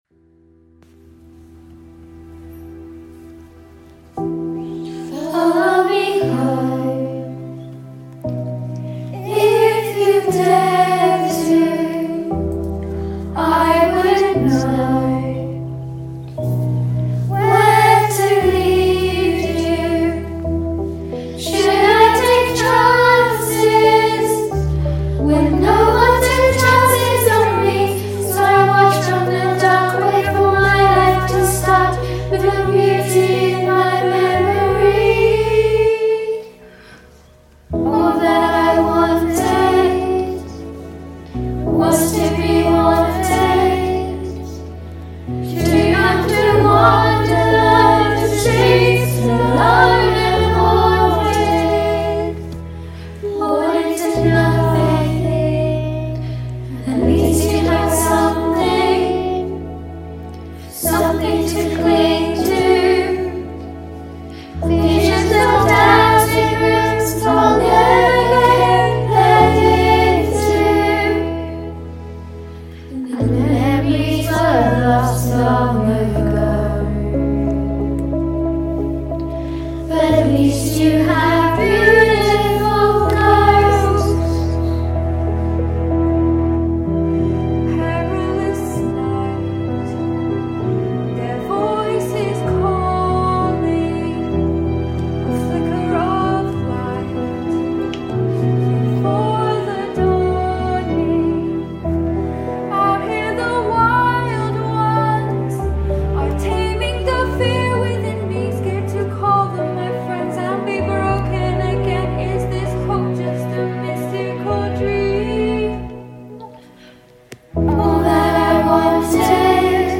Beautiful Ghosts Virtual Choir